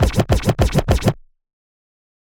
102 BPM Beat Loops Download